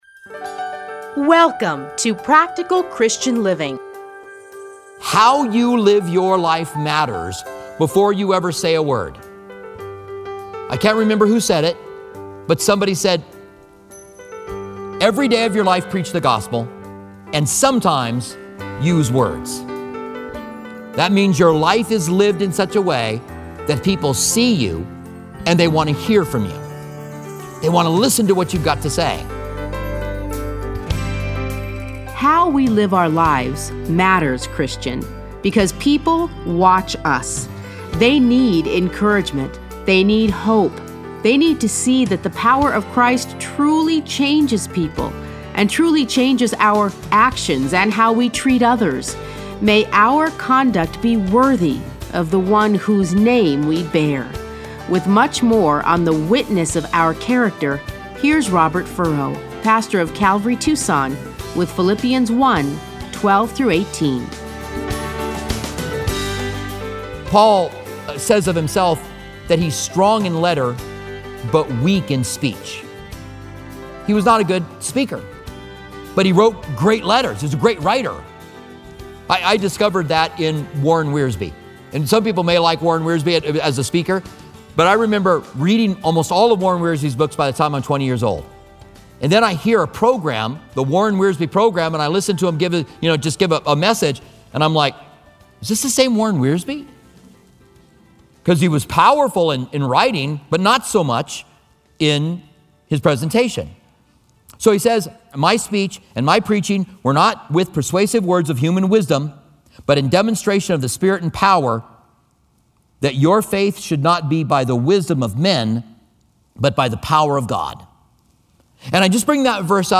Listen to a teaching from Philippians 1:12-18.